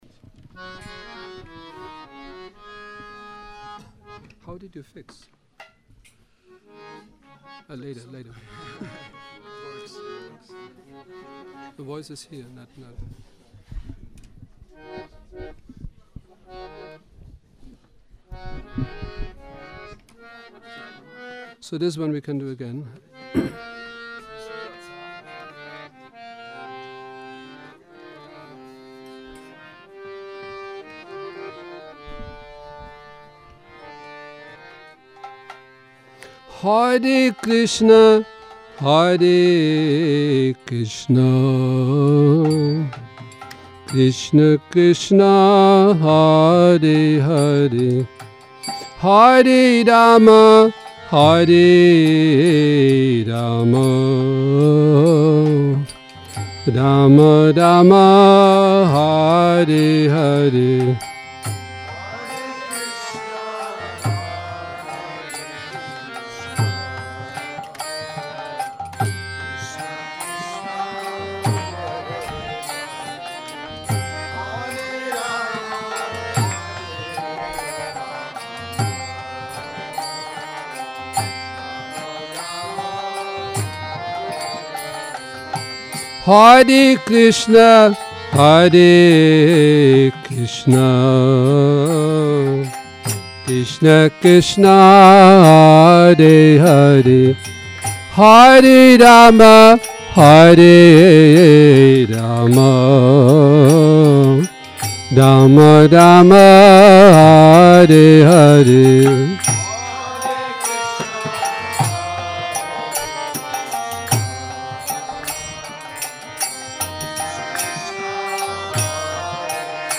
Šrí Šrí Nitái Navadvípačandra mandir
Kírtan